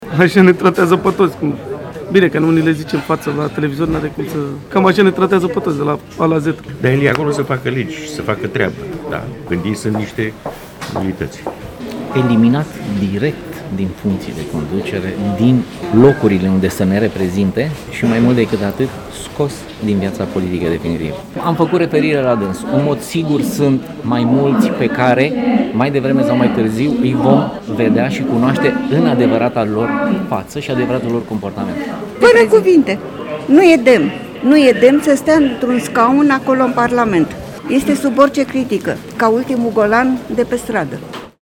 I-am întrebat pe câțiva locuitori ai capitalei dacă se simt reprezentați de un parlamentar cu un asemenea limbaj: